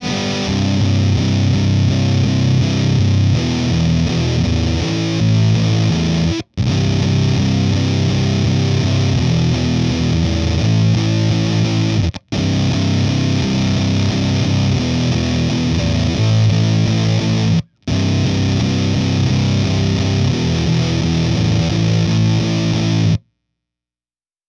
Fuzz and OD together – YES turned up Light OD